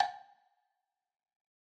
woodblock.ogg